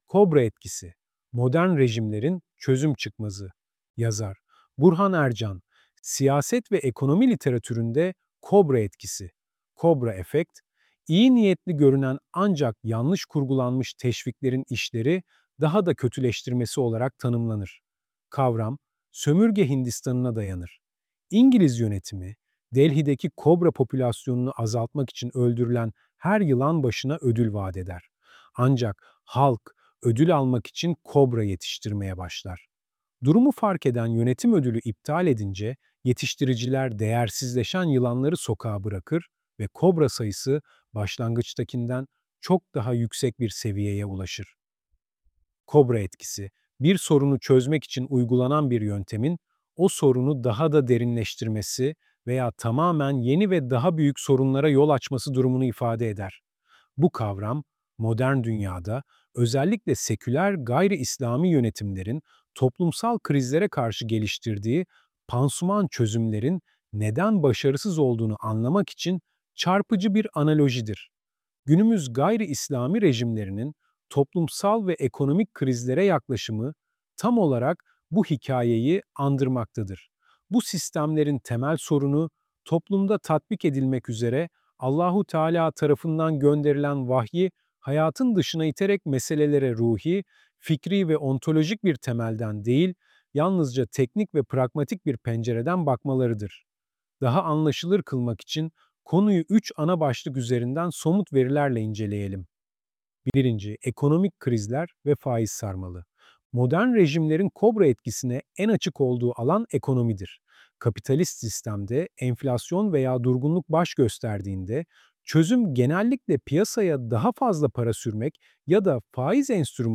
Bu ses yapay zeka tarafından oluşturulmuştur